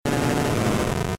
Cri d'Abo K.O. dans Pokémon Diamant et Perle.